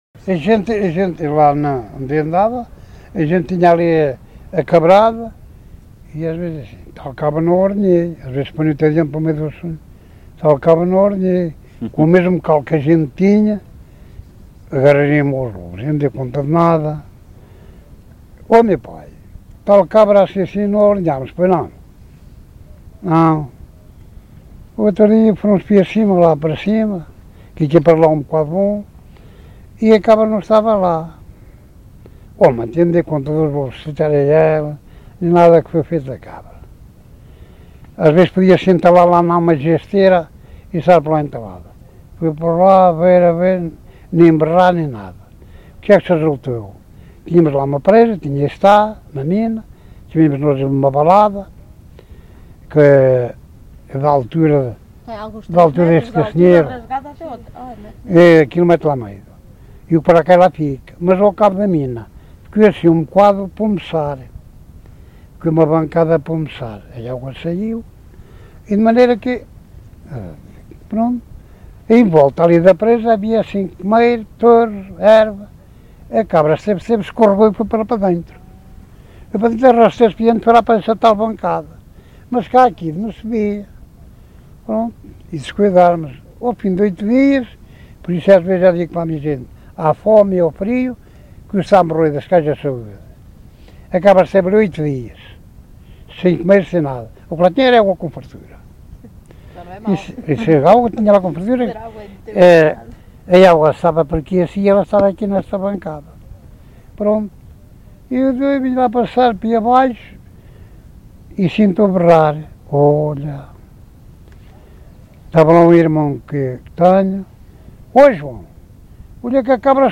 LocalidadeUnhais da Serra (Covilhã, Castelo Branco)